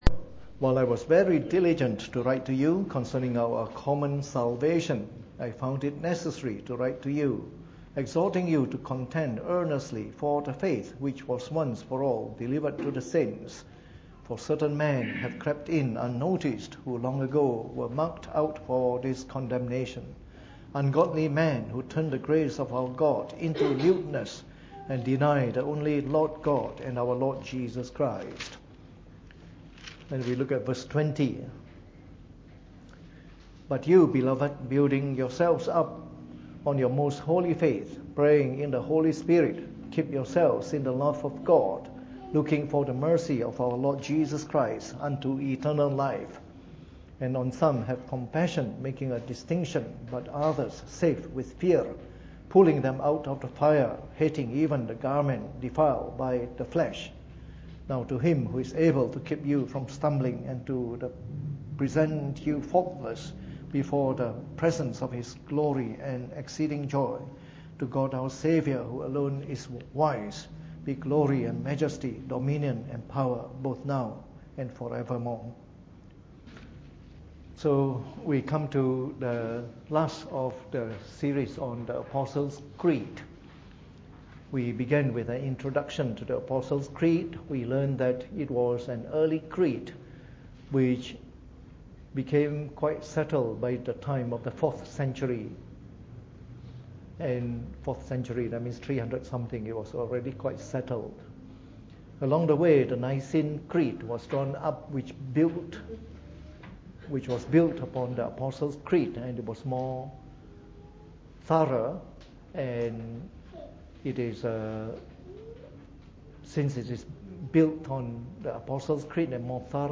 Preached on the 22nd of February 2017 during the Bible Study, from our series on the Apostles’ Creed.